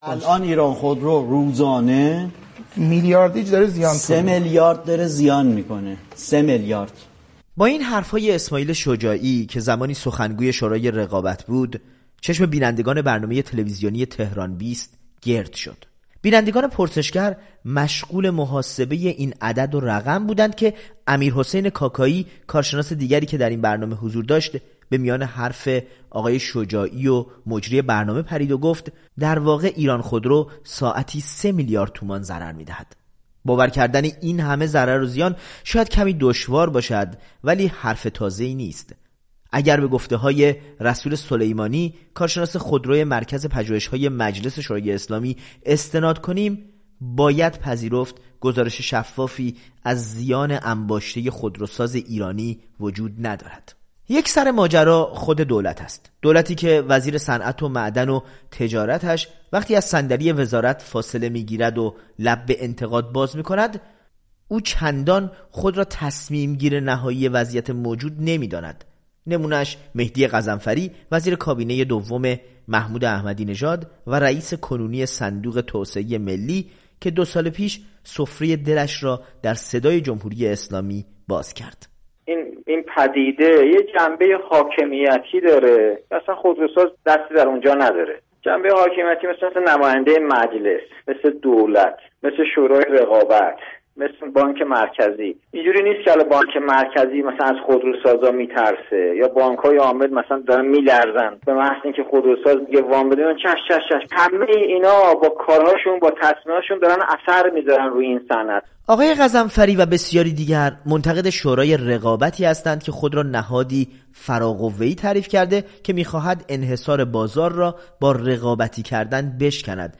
بر زبان آوردن رقم زیان انباشته یکی از این خودروسازی‌های دولتی در سیمای جمهوری اسلامی خبرساز شده است. گزارشی